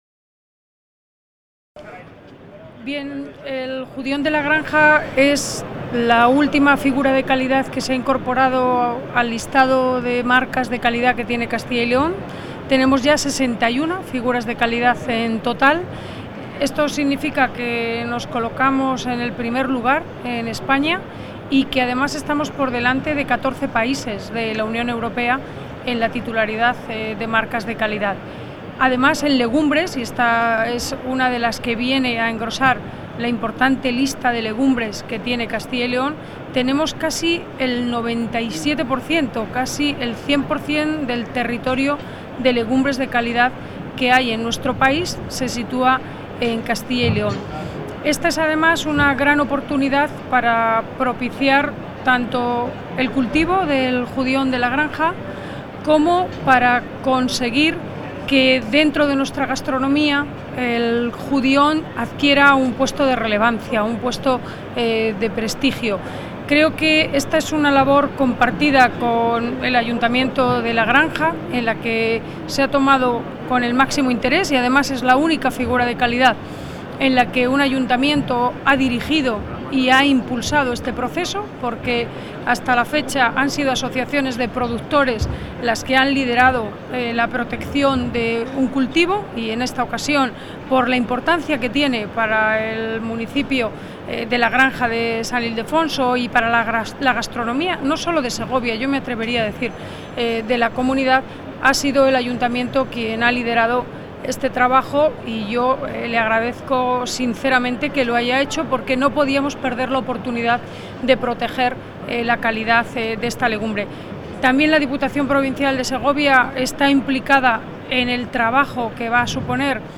Audio Consejera Alimentaria.
La consejera de Agricultura y Ganadería, Silvia Clemente, y el alcalde del Real Sitio de San Ildefonso, José Luis Vázquez Fernández, han presentado en la Feria Alimentaria de Barcelona la nueva Marca de Garantía ‘Judión de La Granja’, que fue registrada oficialmente por la Oficina de Patentes y Marcas el pasado 3 de enero.